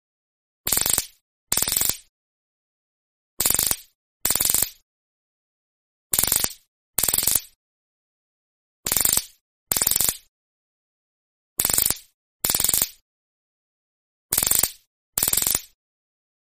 Tiếng phóng Dùi cui điện, tiếng Chích điện rẹt rẹt
Thể loại: Đánh nhau, vũ khí
Description: Hiệu ứng âm thanh tiếng súng điện, tiếng dùi cui điện phóng điện rẹt rẹt, tiếng máy chích điện kêu tệch tệch tệch, electric stun gun sound effect chân thực nhất dùng để dựng phim, edit video chất lượng cao.
tieng-phong-dui-cui-dien-tieng-chich-dien-ret-ret-www_tiengdong_com.mp3